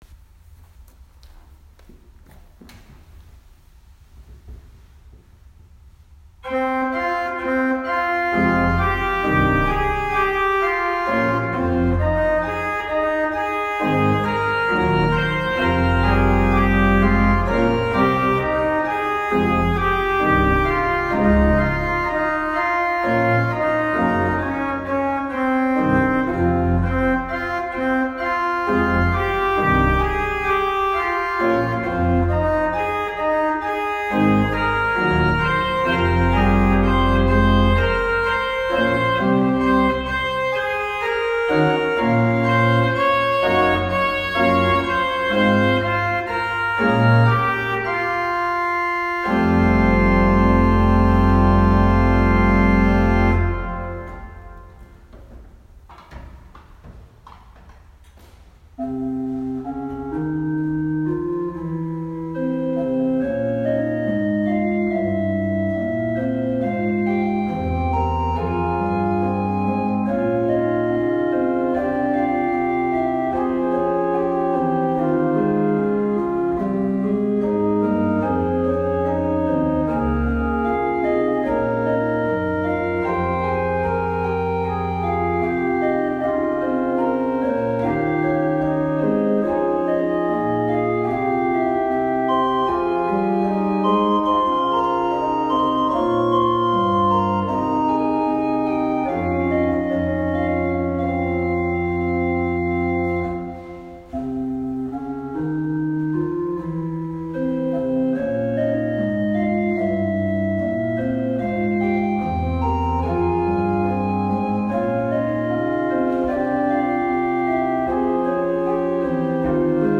Organ Partita